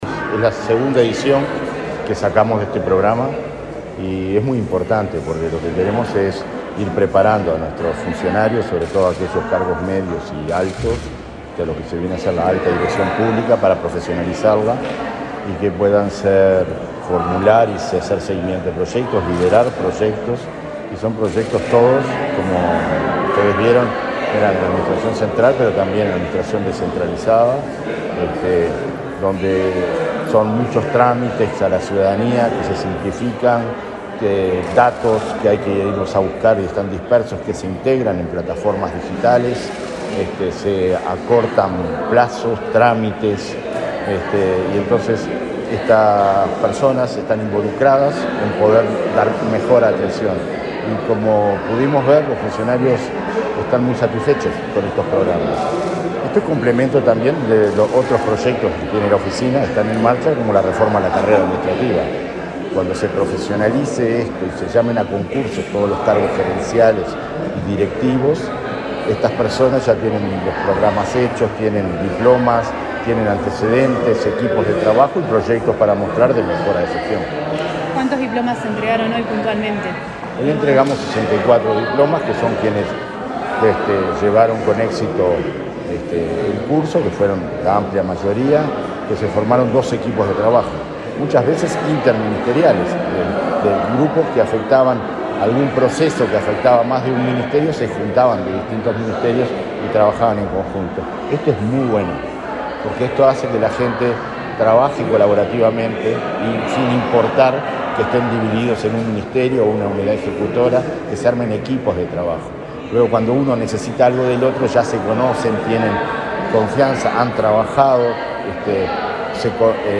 Entrevista al director de la ONSC, Conrado Ramos
Este miércoles 14, en la Torre Ejecutiva, el director de la Oficina Nacional del Servicio Civil (ONSC), Conrado Ramos, dialogó con Comunicación